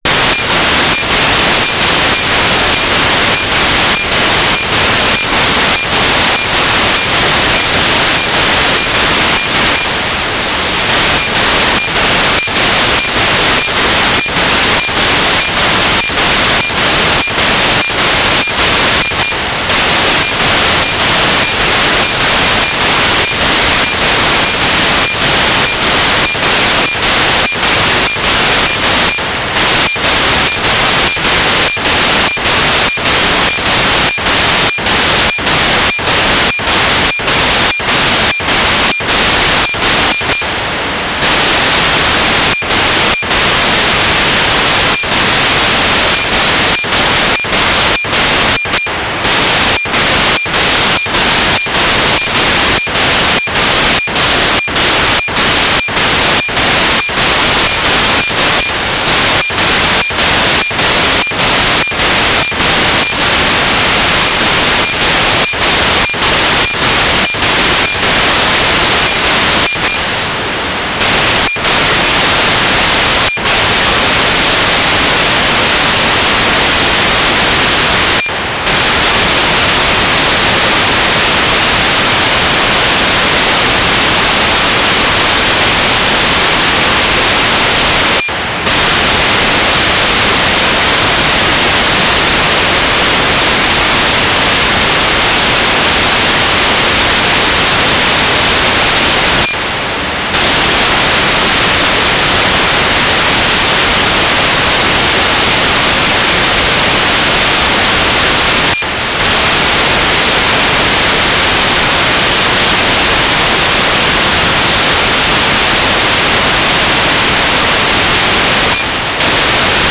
"transmitter_mode": "MSK AX.100 Mode 5",
"transmitter_baud": 9600.0,